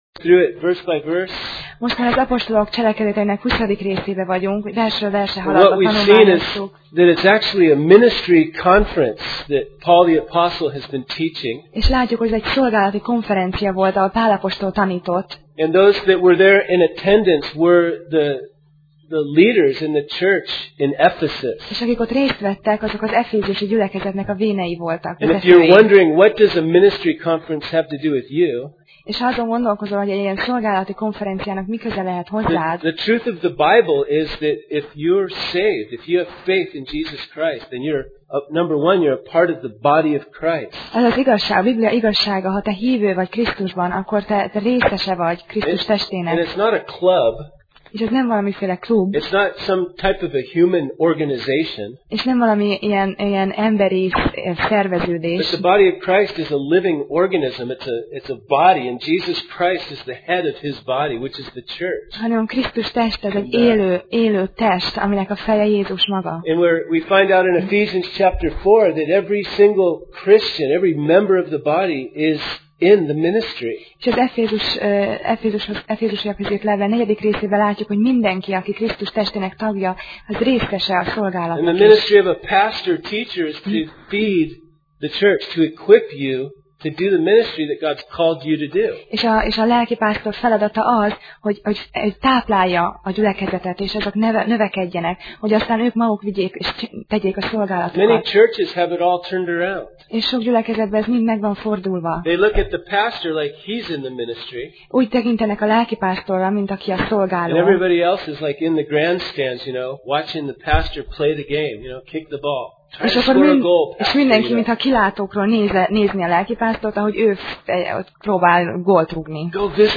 Passage: Apcsel (Acts) 20:33-35 Alkalom: Vasárnap Reggel